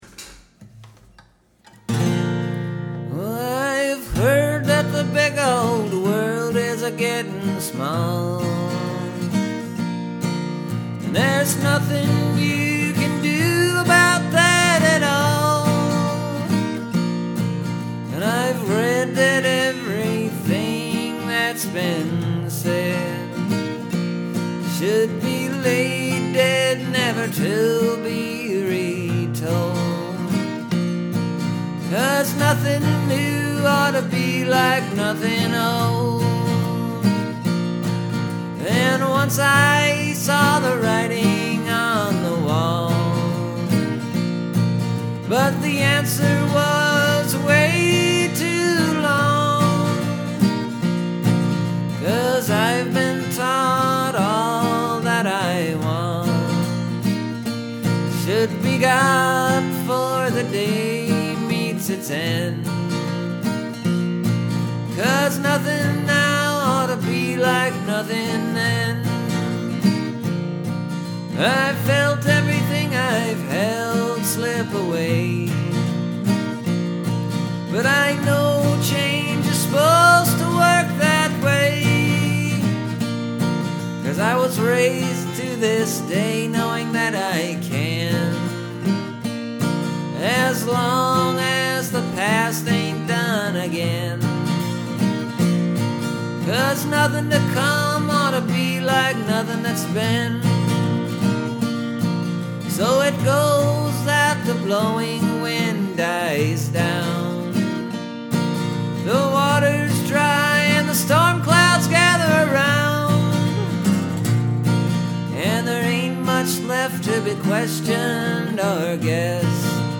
So this is a cleaner version.